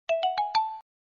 chat_msg.mp3